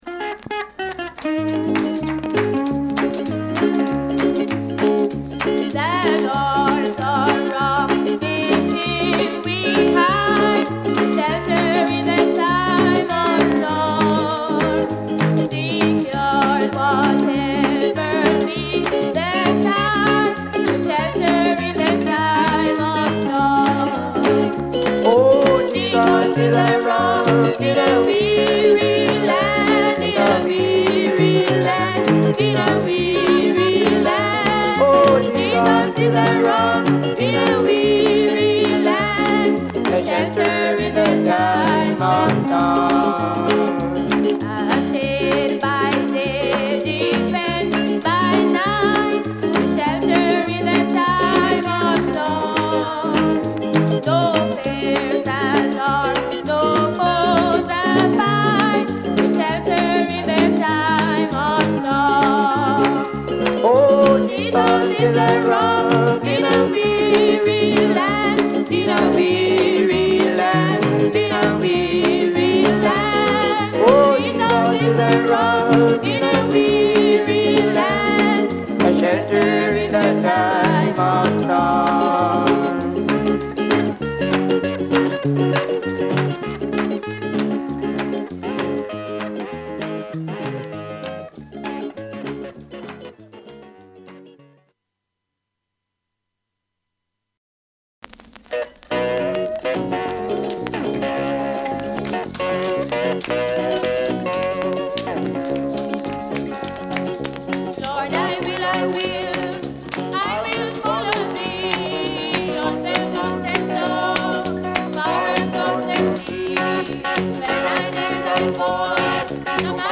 Genre: Reggae Gospel